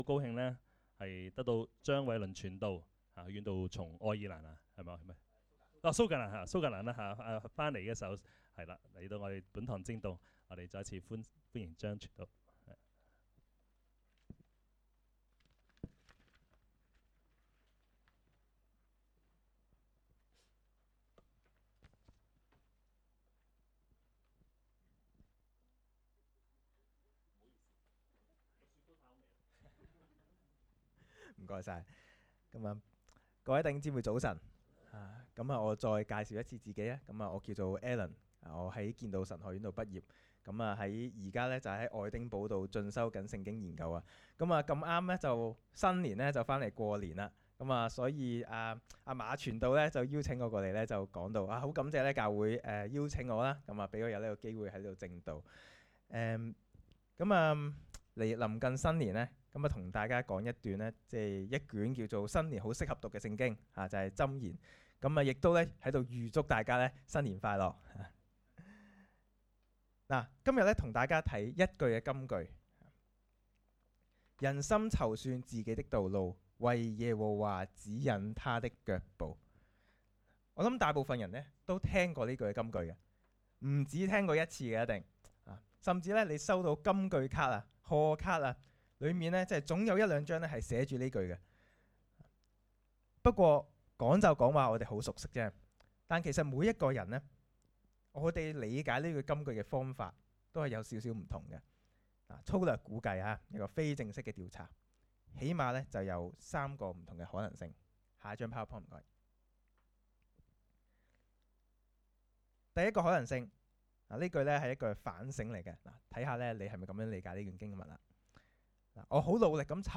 講道 ：成事在天，謀事在人